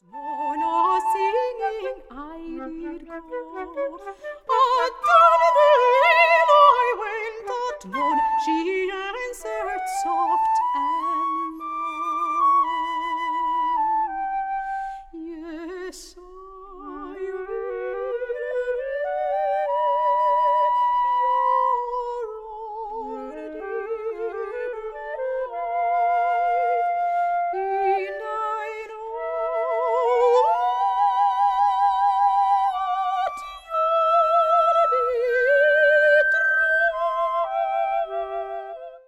Portuguese Soprano
CONTEMPORARY IRISH SONGS - flute